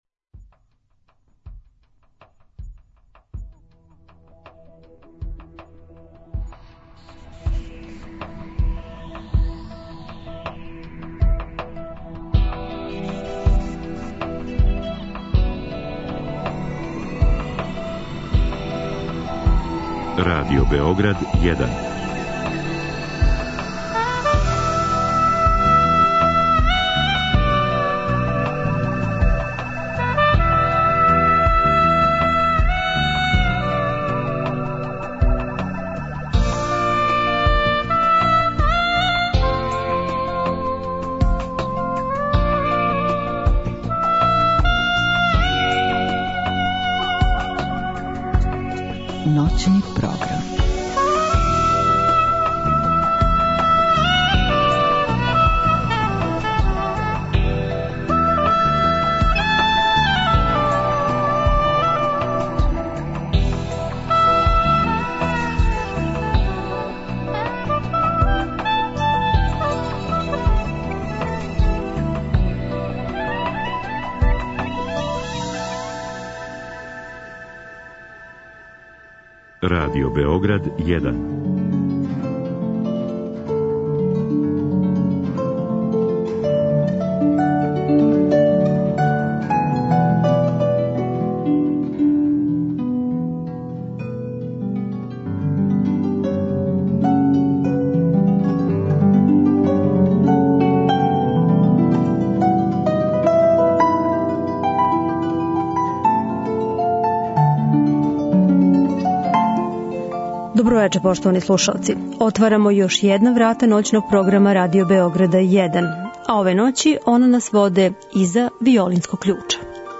И ове ноћи у студију Радио Београда 1